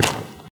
Add sound for turf
pedology_turf_footstep.1.ogg